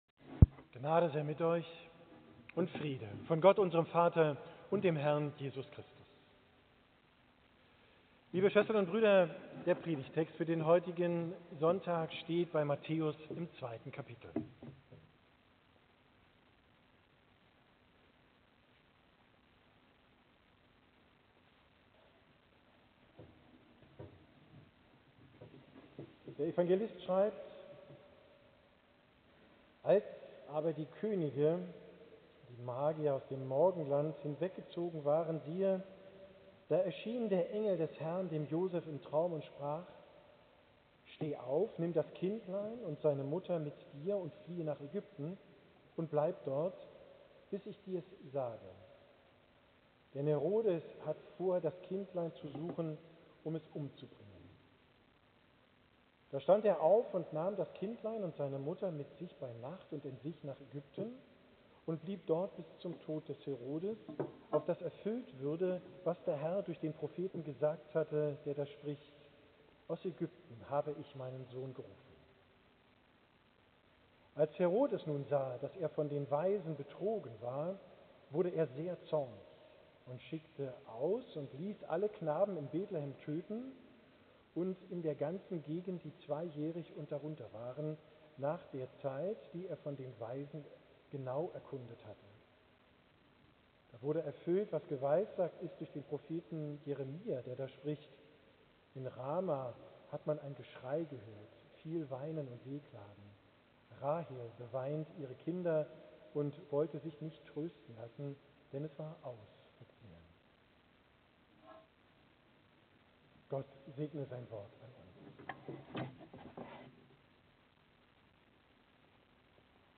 Predigt vom 1.